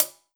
soft-hitnormal4.wav